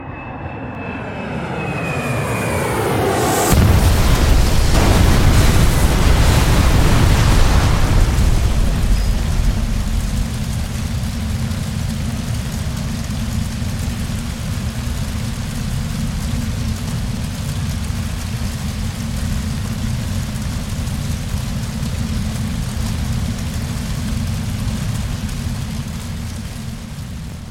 Airplane crash 1
Aircraft Airplane Crash Vintage sound effect free sound royalty free Memes